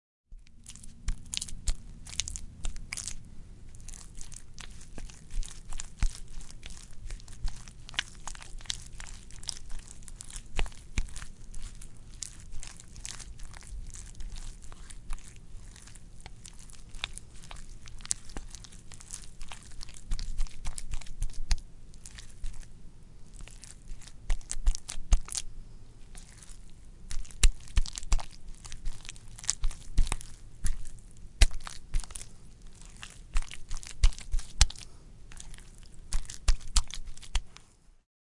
Download Free Squish Sound Effects
Squish